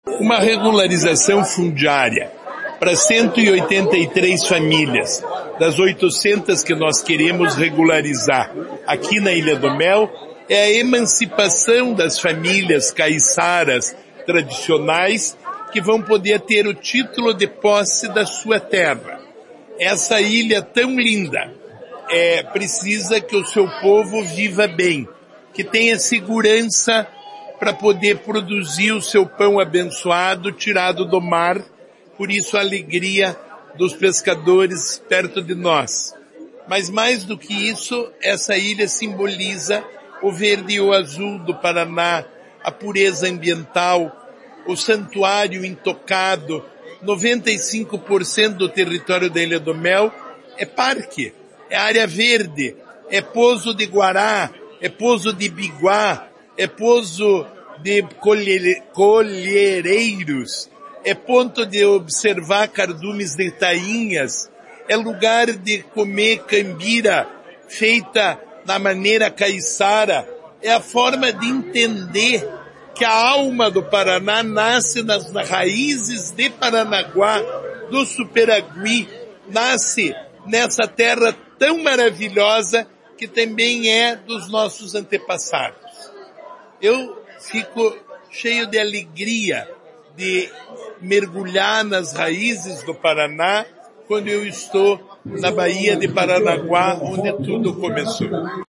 Sonora do secretário do Desenvolvimento Sustentável, Rafael Greca, sobre os anúncios na Ilha do Mel